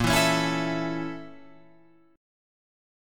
A#mM7b5 chord